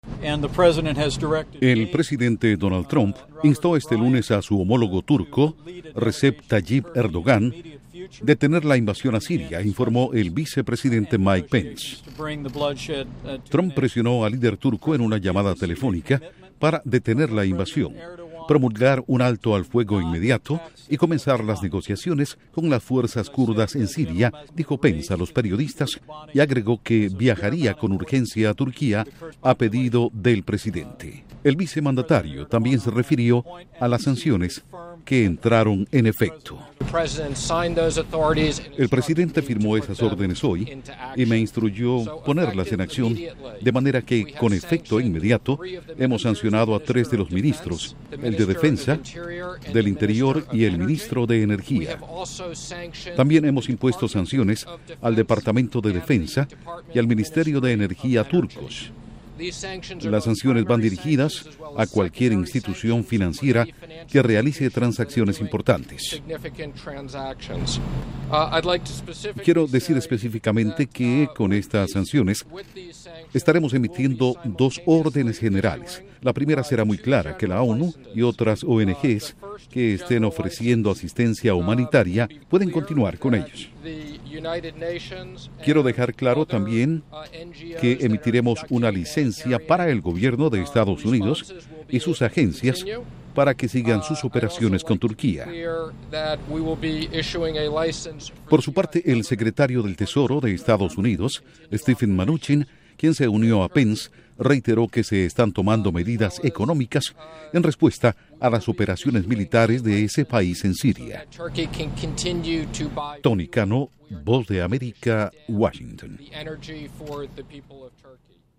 Con declaraciones de Mike Pence/Vicepresidente EE.UU.